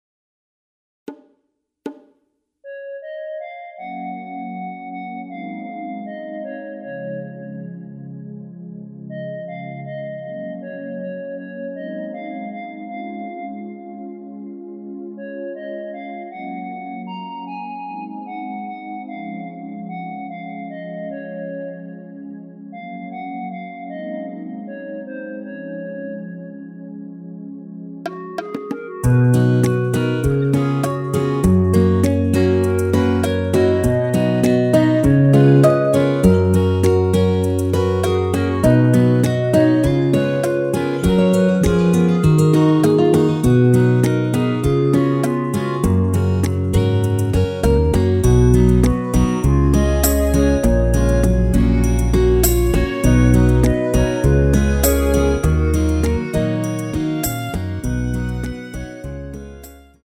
시작부분이 반주가 없이 진행 되는곡이라 스트링으로 편곡 하여놓았습니다.(미리듣기 참조)
원키 멜로디 포함된 MR입니다.
F#
앞부분30초, 뒷부분30초씩 편집해서 올려 드리고 있습니다.